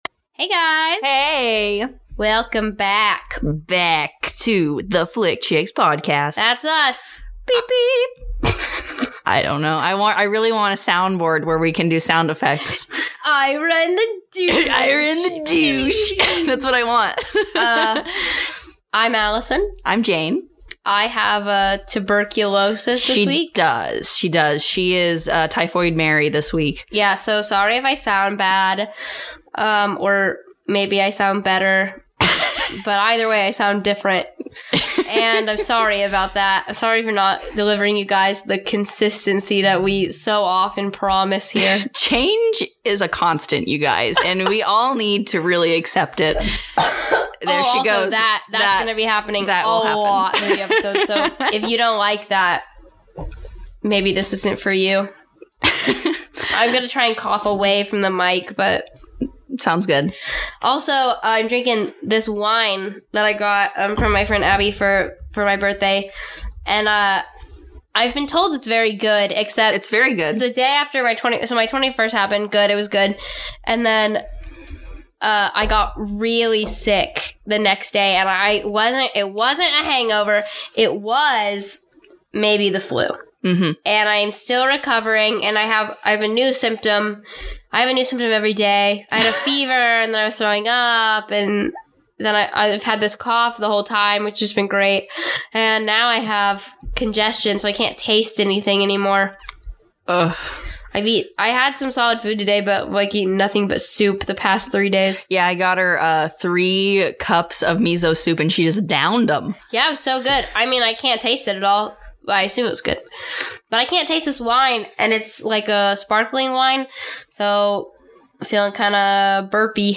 Join us for fun, laughter, and a lot of coughing.